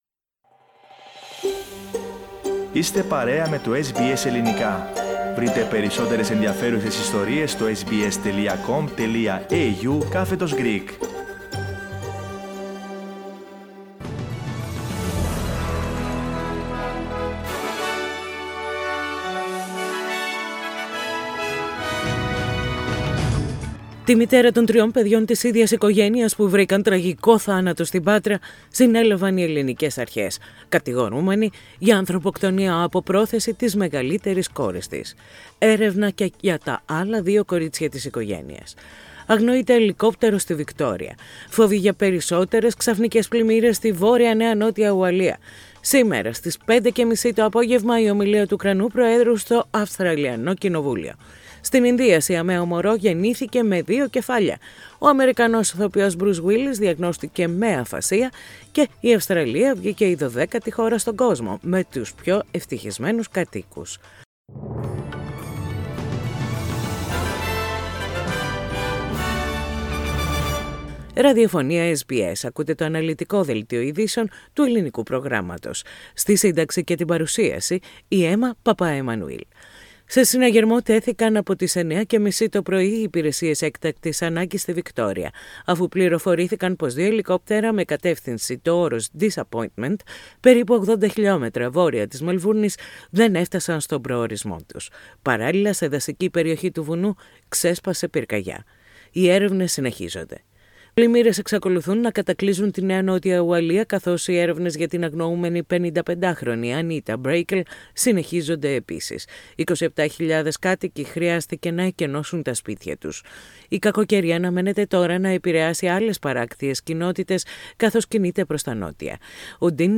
Δελτίο Ειδήσεων - Πέμπτη 31.3.22